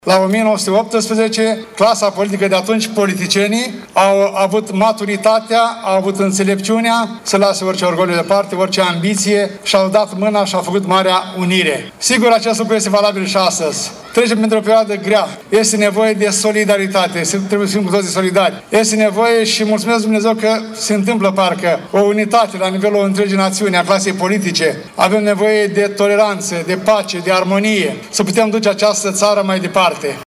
Primarul municipiului Suceava ION LUNGU a spus, la ceremonia desfășurată la statuia Bucovina Înaripată, că Marea Unire trebuie prețuită și dusă mai departe generațiilor viitoare.